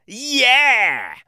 Йеее муж